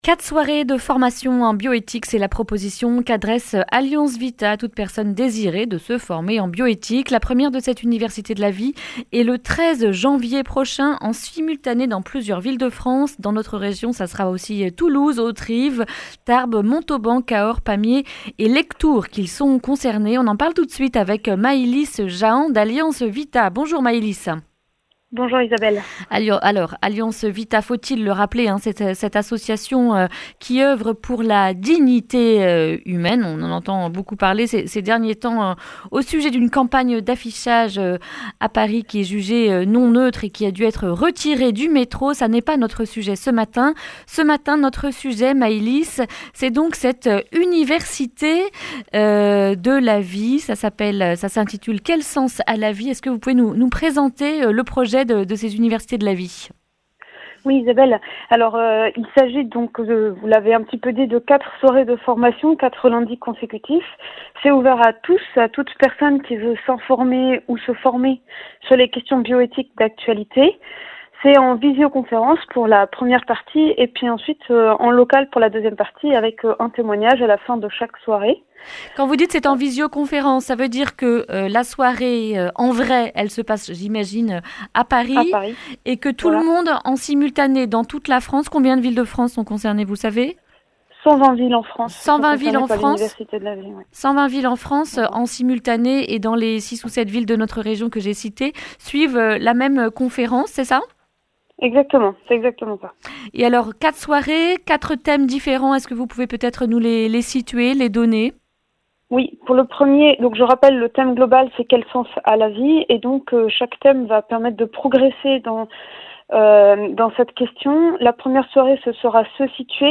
mardi 7 janvier 2020 Le grand entretien Durée 11 min